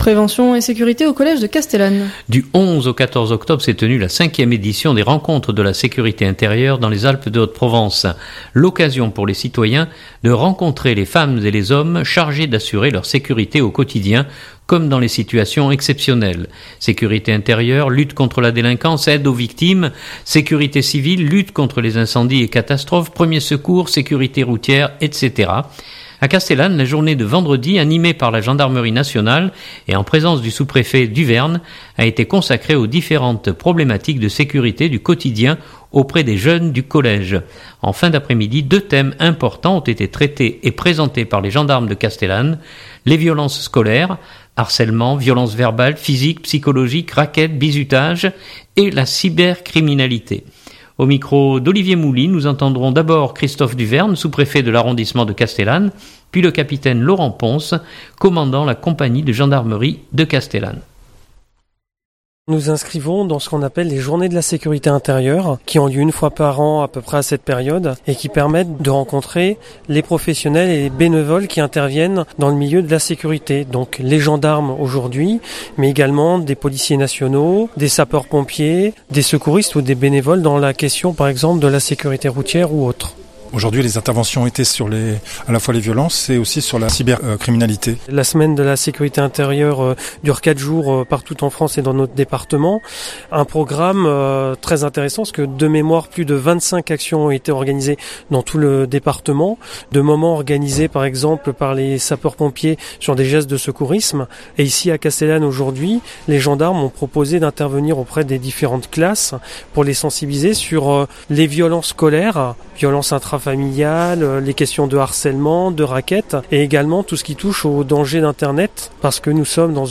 Journal du 2017-10-16 - Castellane - Rencontres de Sécurité Intérieure.mp3 (2.91 Mo)